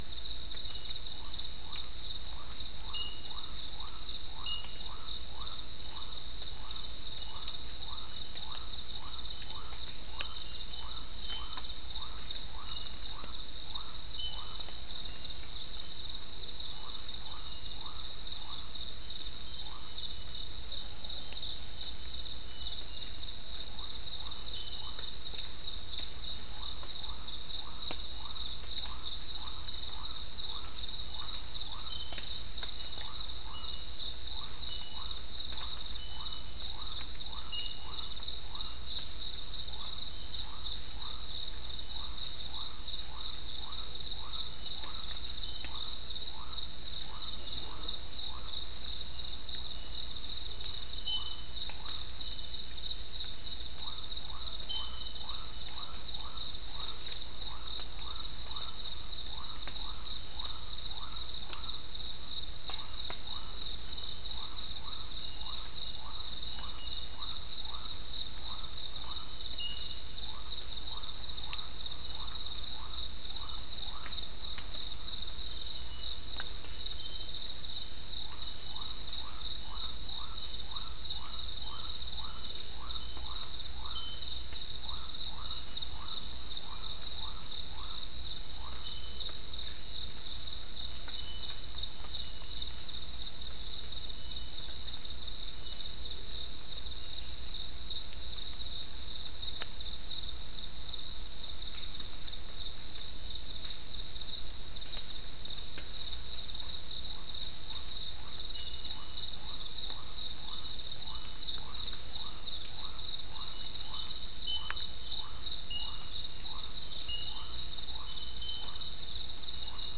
Soundscape Recording Location: South America: Guyana: Turtle Mountain: 4
Recorder: SM3